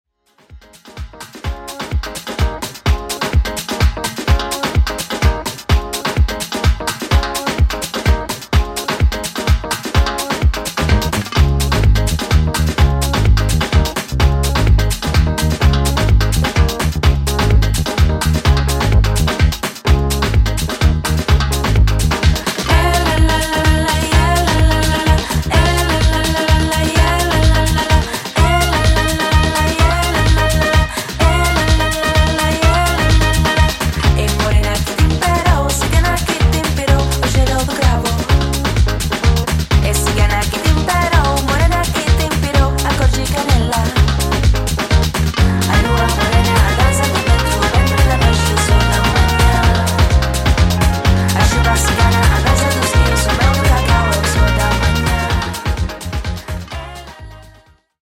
Heavy vibes